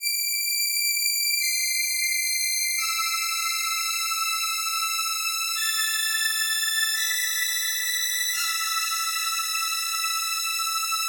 Sorar Strings 01.wav